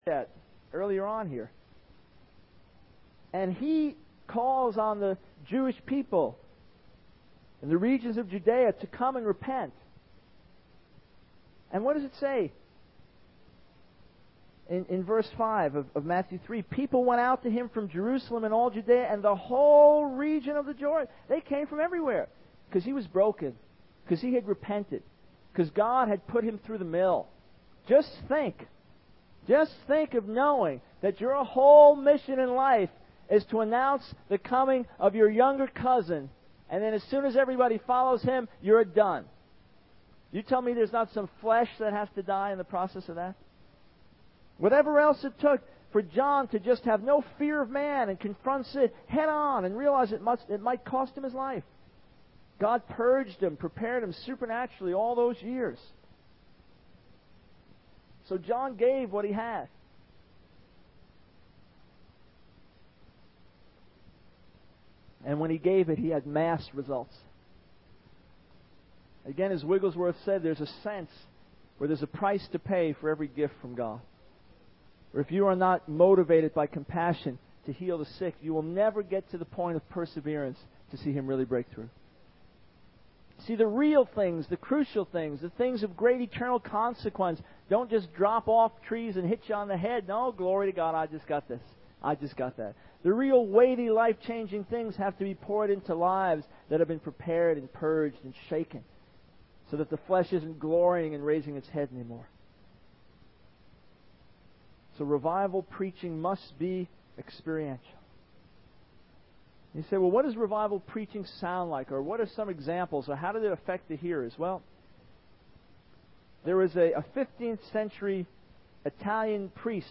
In this sermon, the preacher describes the powerful preaching of Charles Finney, a renowned evangelist.